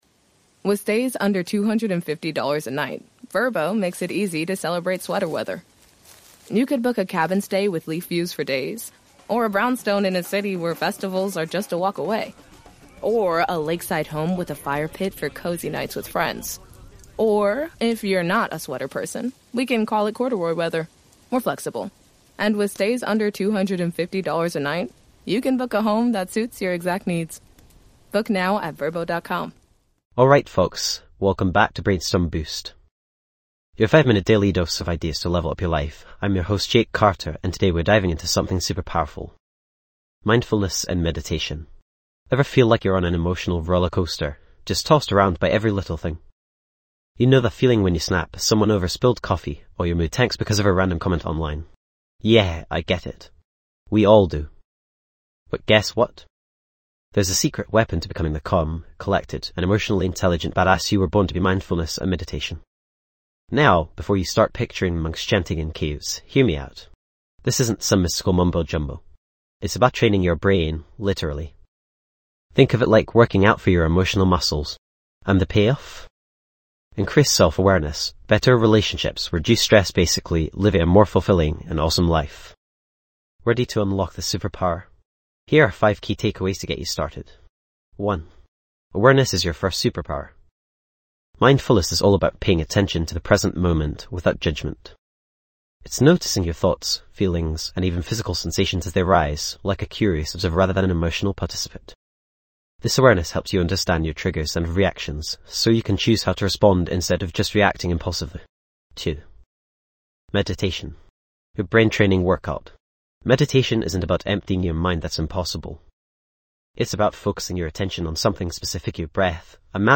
- Experience a guided meditation session for emotional clarity
This podcast is created with the help of advanced AI to deliver thoughtful affirmations and positive messages just for you.